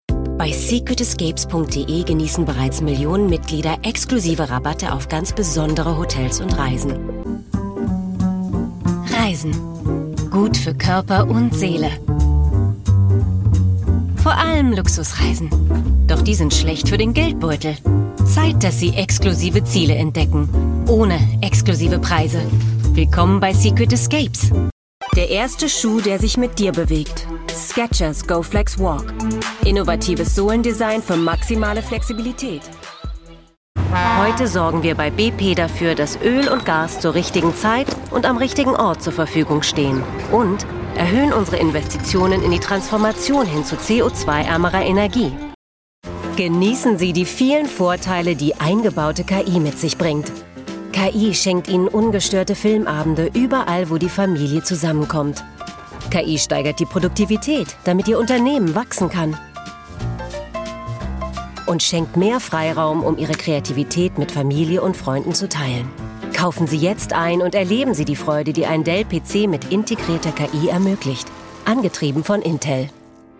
European, German, Female, Home Studio, 30s-50s